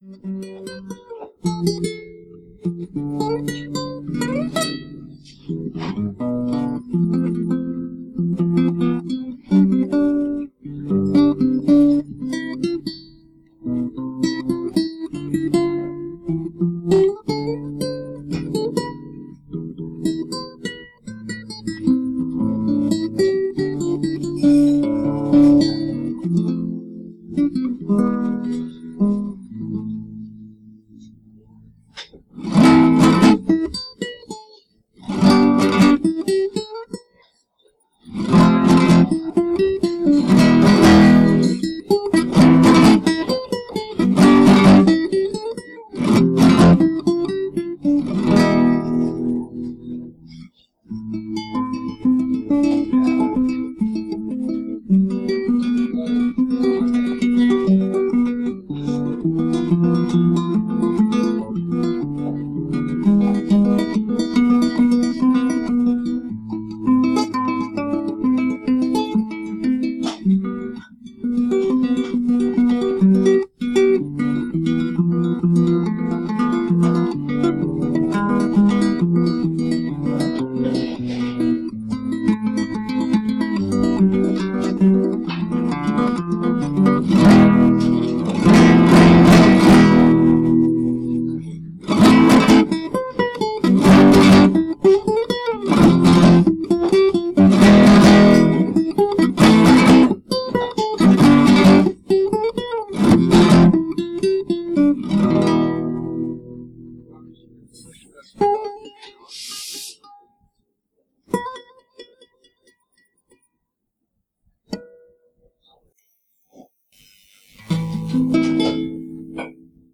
Kytarové sólo